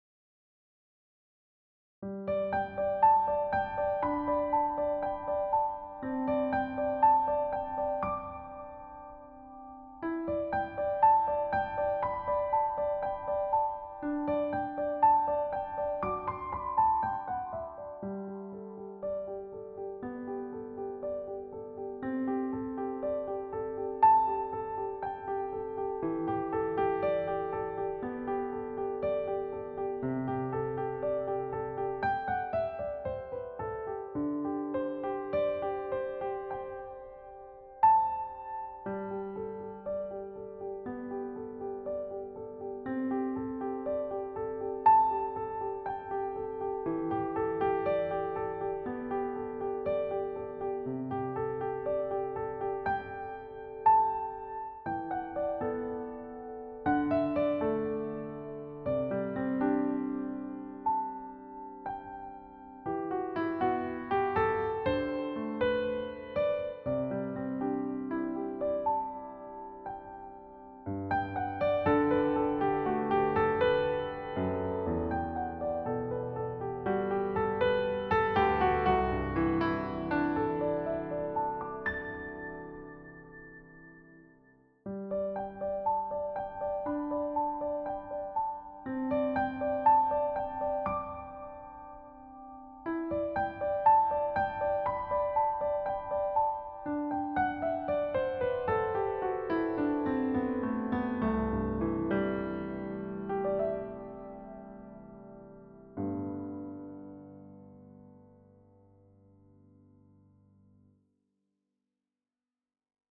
Key: G Major
Time Signature: 3/4